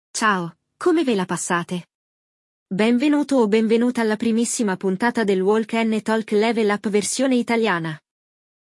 Neste episódio do nosso podcast, você vai acompanhar dois amigos que conversam sobre o teste de direção para obter a carteira de motorista!